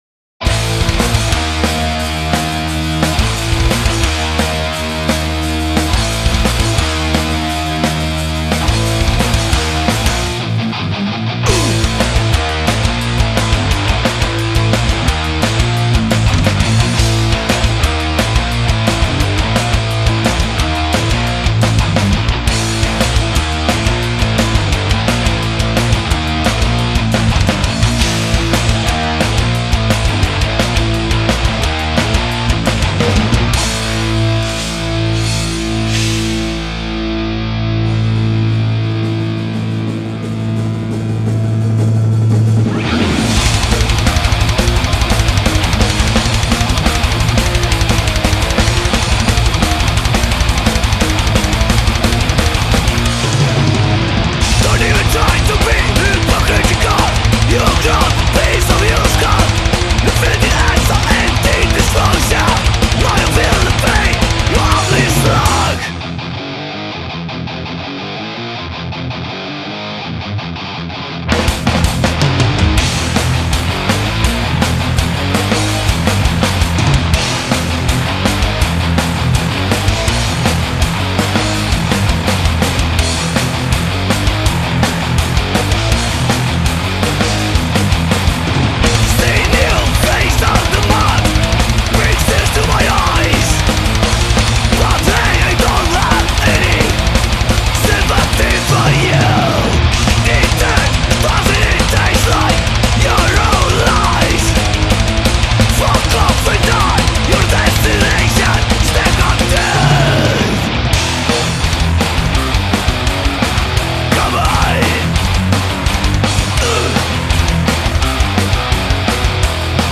Power-trio
vanakooli thrash-metal
Kaheksa adrenaliinist ja testosteroonist nõretavat lugu